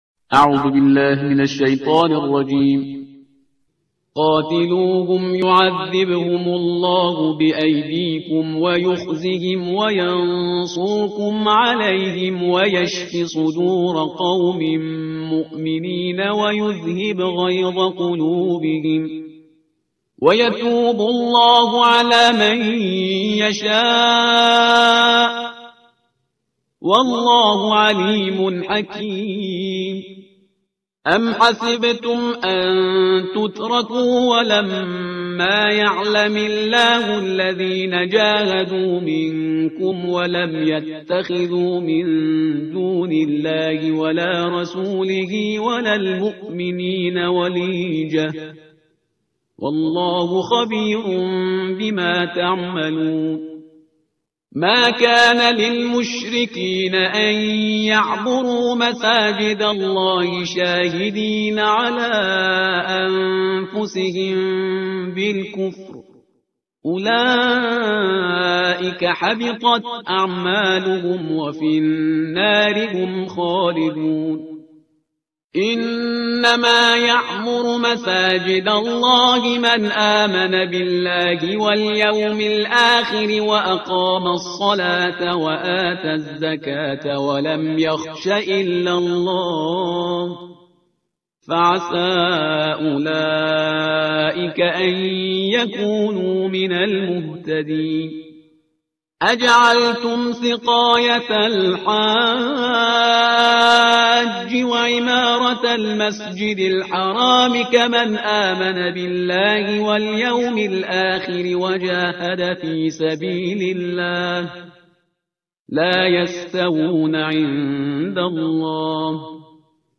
ترتیل صفحه 189 قرآن با صدای شهریار پرهیزگار